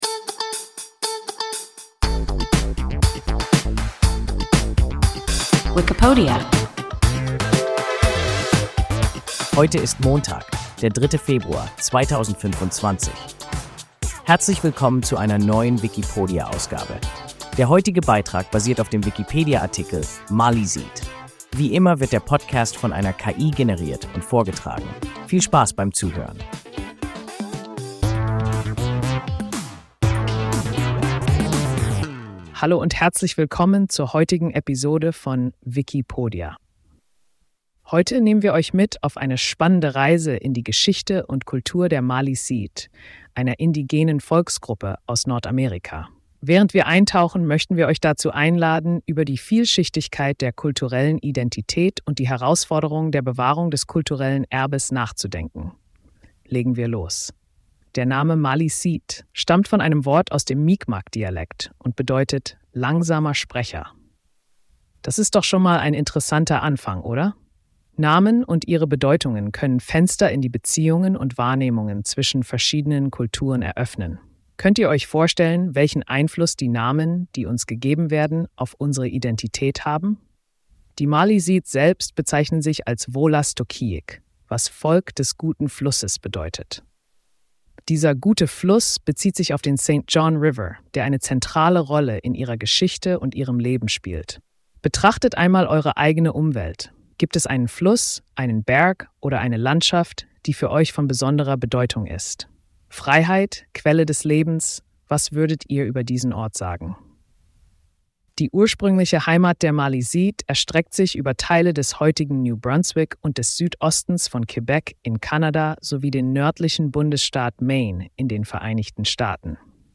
Maliseet – WIKIPODIA – ein KI Podcast